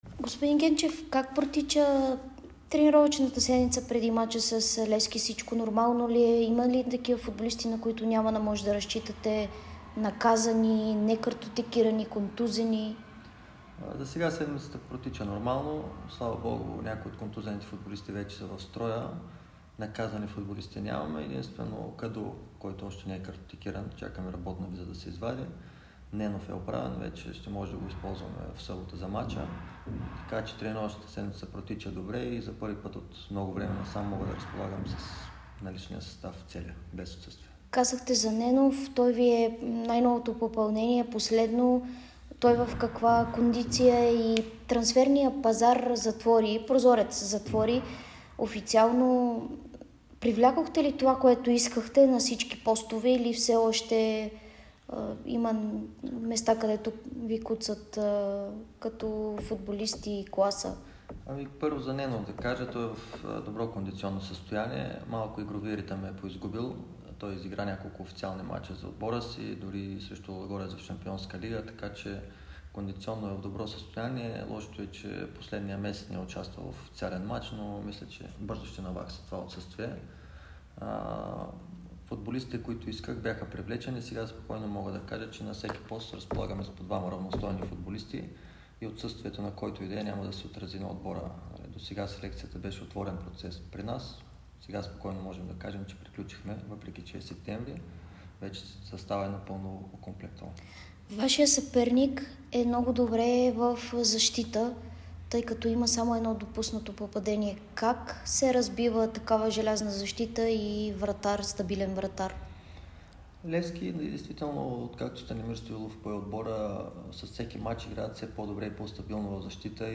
Старши треньорът на Локомотив София Станислав Генчев даде специално интервю за Дарик радио и dsport преди домакинството на Левски.